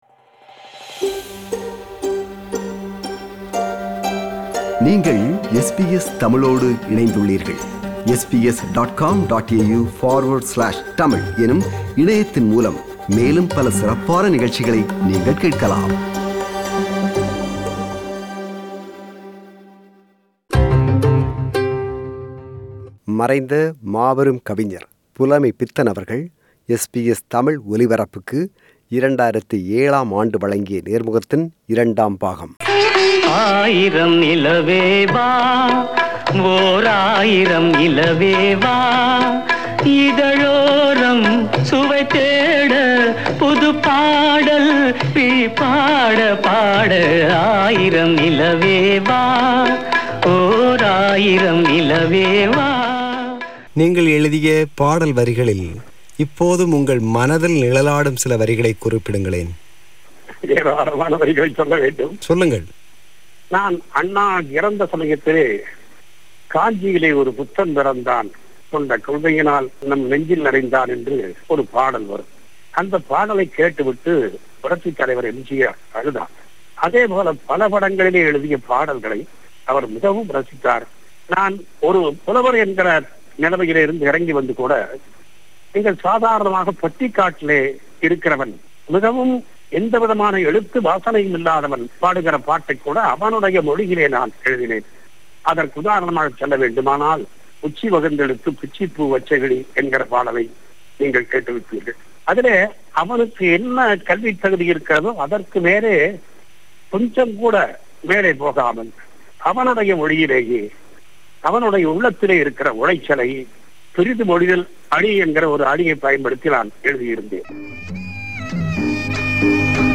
Interview with Poet Pulamaipithan – Part 2